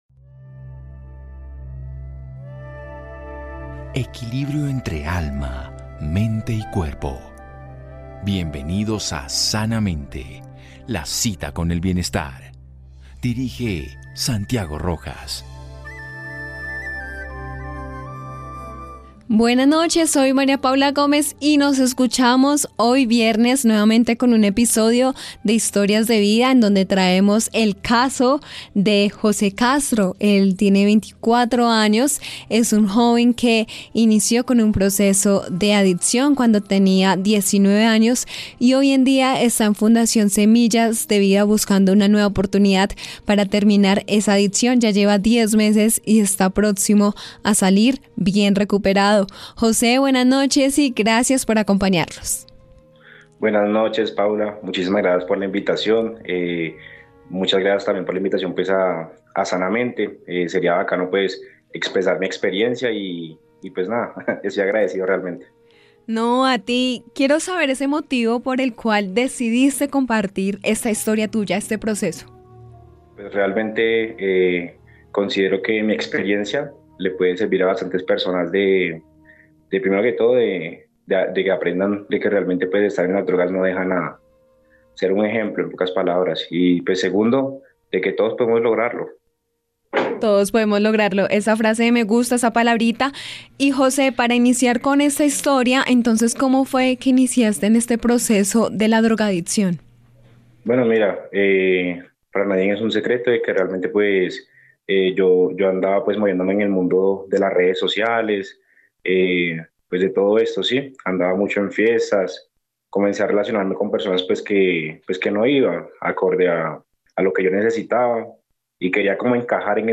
Conversamos con un paciente que se encuentra en proceso de recuperación después de caer en el consumo adictivo a las drogas, quien demuestra que siempre es posible salir adelante.